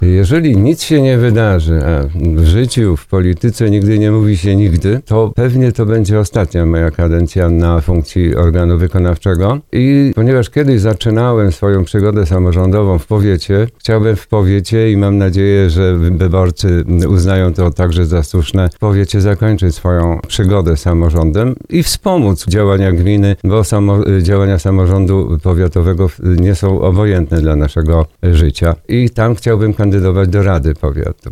Samorządowiec podczas rozmowy Słowo za Słowo oznajmił, że wszystko wskazuje na to iż na dobre zakończy piastowanie swojego urzędu w gminie Szczucin.